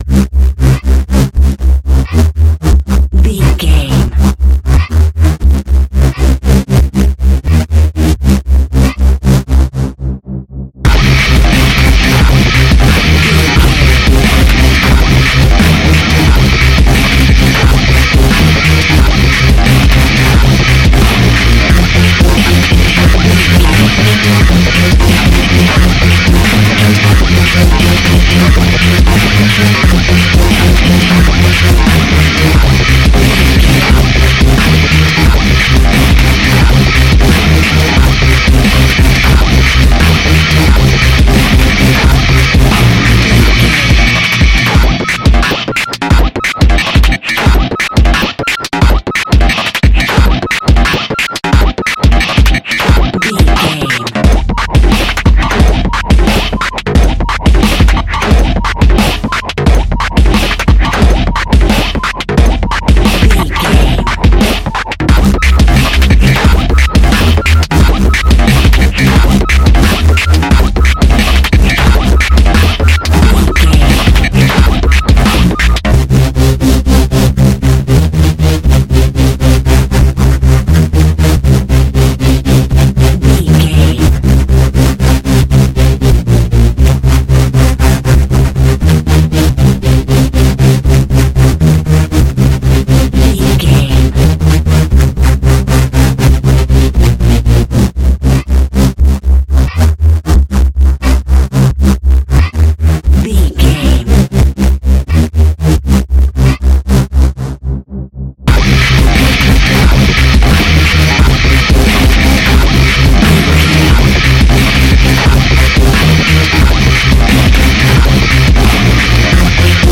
Epic / Action
Fast paced
Aeolian/Minor
aggressive
powerful
dark
intense
synthesiser
drum machine
futuristic
breakbeat
energetic
dubstep instrumentals
synth leads
synth bass